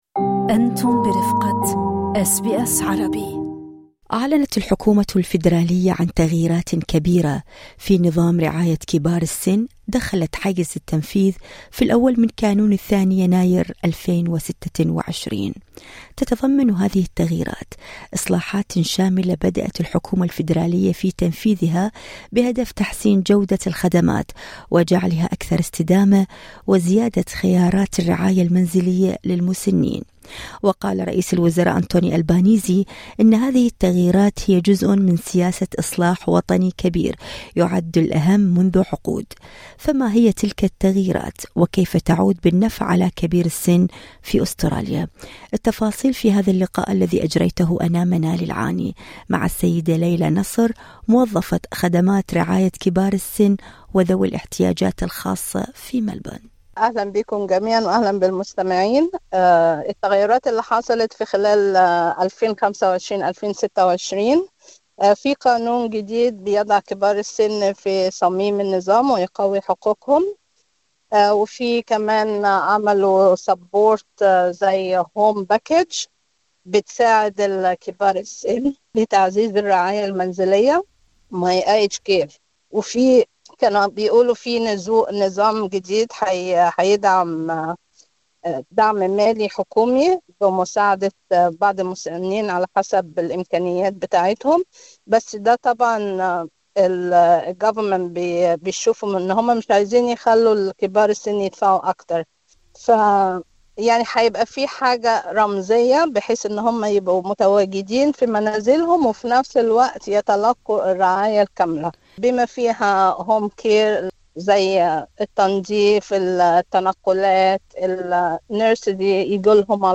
فما هي تلك التغييرات وكيف تعود بالنفع على كبير السن في أستراليا؟ التفاصيل في اللقاء الصوتي اعلاه